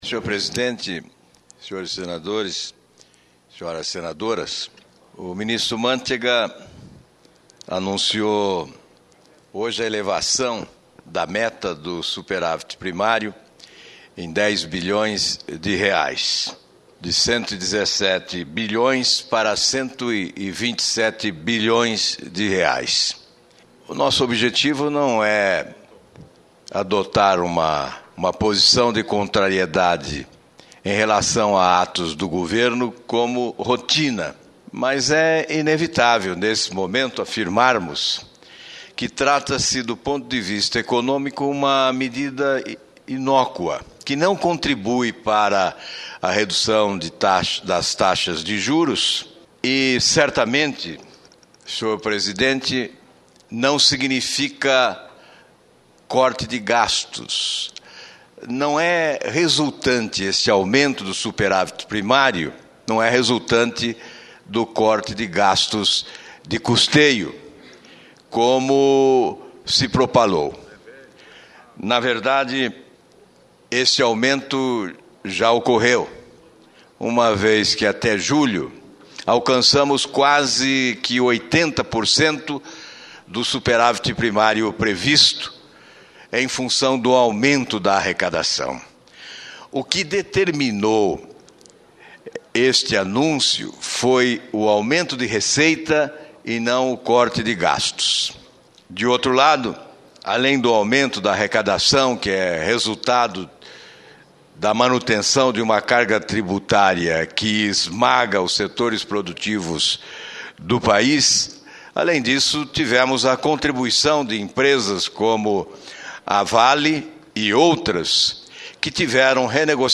Em aparte, o senador Randolfe Rodrigues (PSOL-AP) apoiou o discurso de Alvaro Dias e disse que também está preocupado com os rumos econômicos do Brasil.